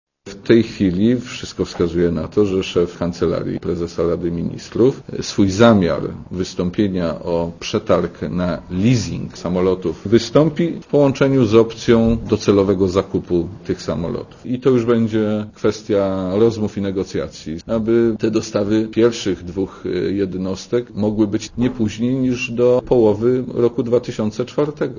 Mówi minister Szmajdziński (97 KB)
"Rozpisanie tego przetargu to absolutna konieczność" - powiedział minister podczas konferencji prasowej w Wyższej Szkole Oficerskiej we Wrocławiu.